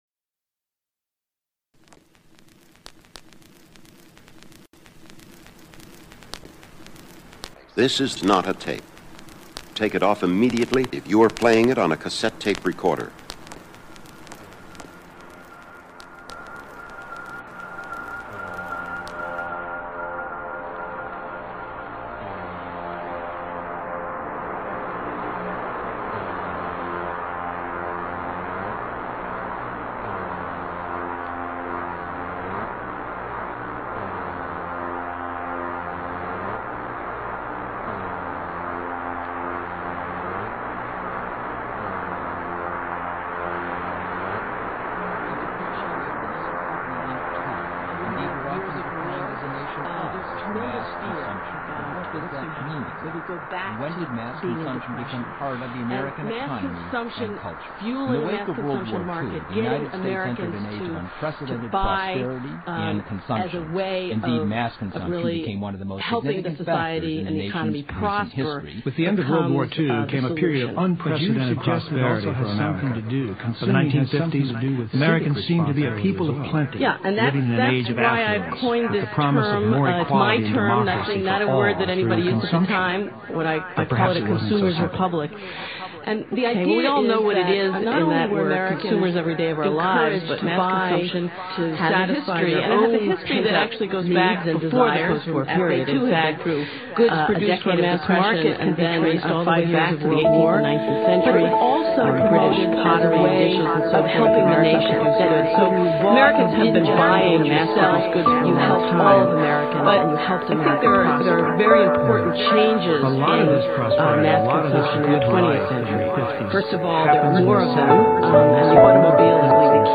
Spherical sounds accompany the listener into other worlds.